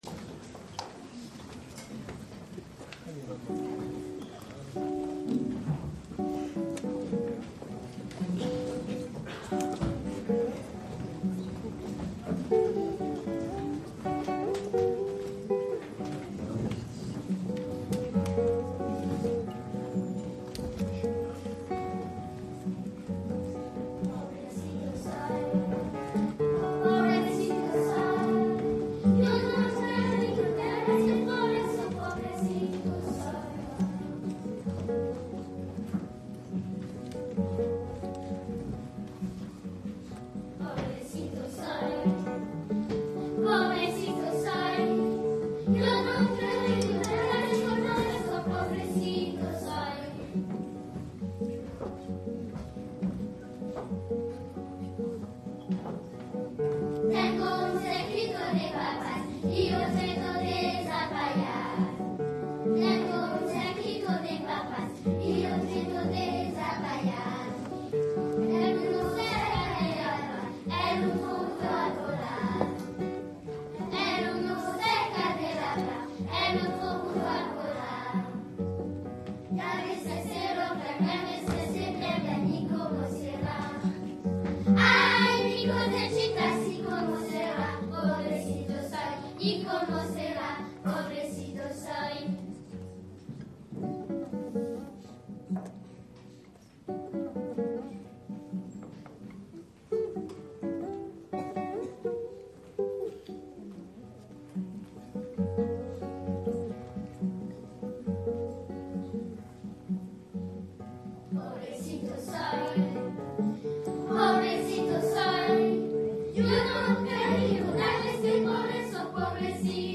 Plus que de mots pour raconter la chaleur, l’attention du public, les petits couacs et les grandes réussites musicales, ce que vous attendez sûrement, ce sont les photos de notre soirée tango.
Ci-dessous quelques extraits audio du spectacle... et un extrait vidéo.